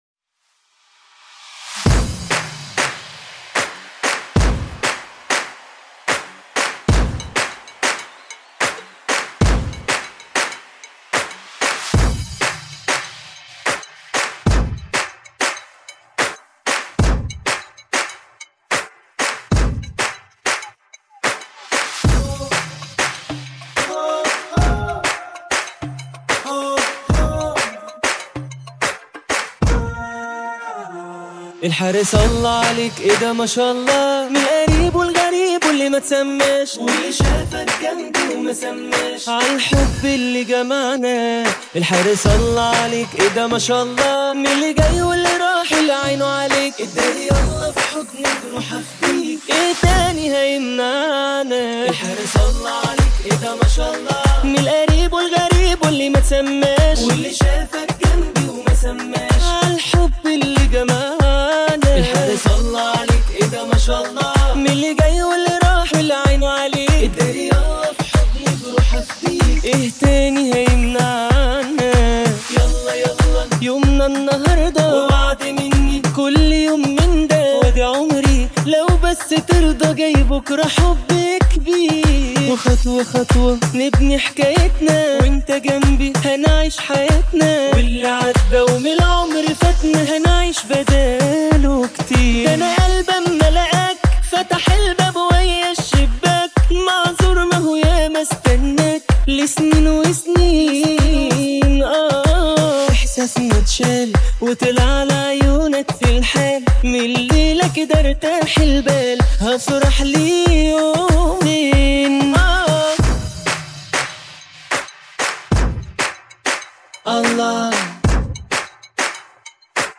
أغانيك المفضلة بدون المعازف الموسيقية